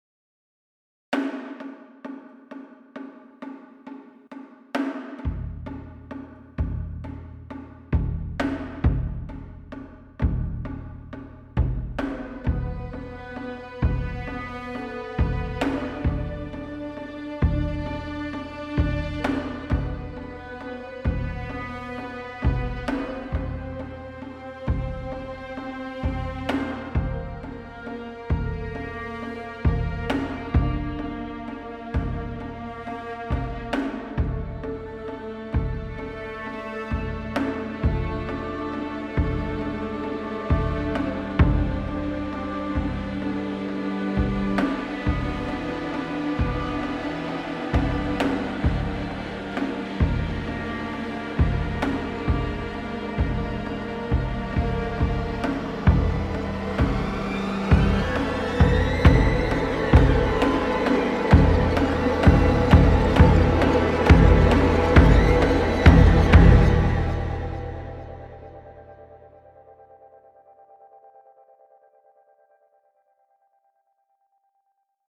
Building, Japanese Percussion, Strings